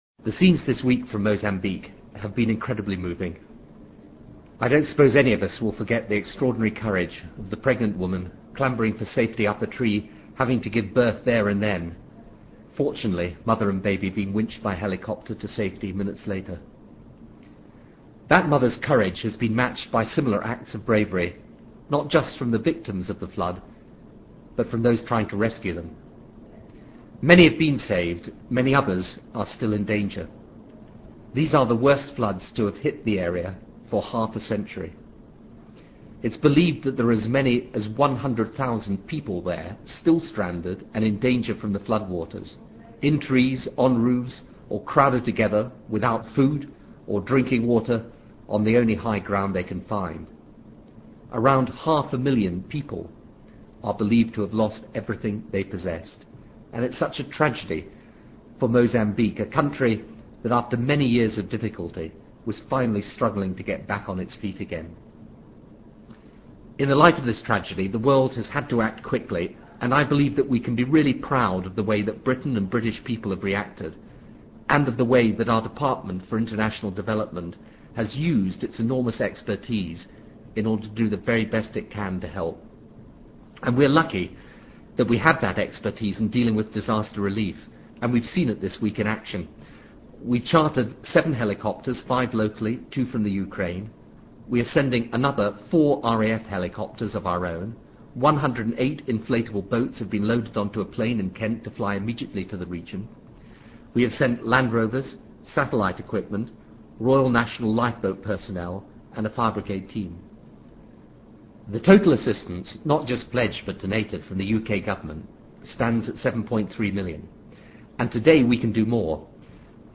TRANSCRIPT OF THE PRIME MINISTER'S BROADCAST ON 3 MARCH 2000